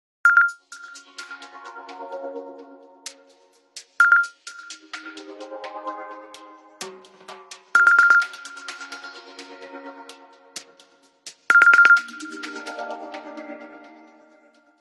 Clock alert 2.aac